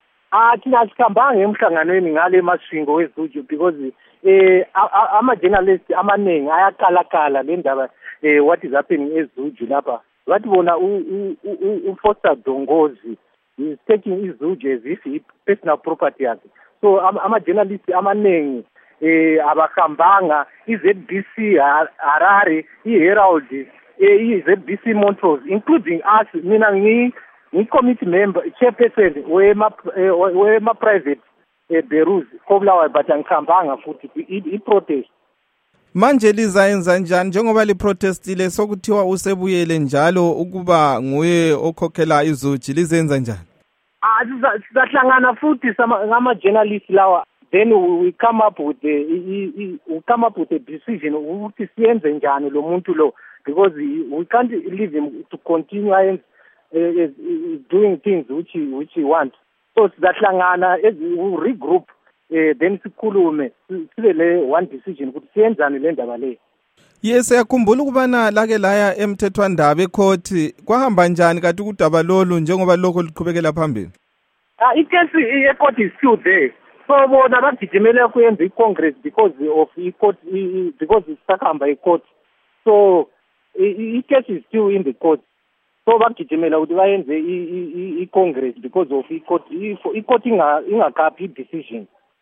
Embed share Ingxoxo loMnu.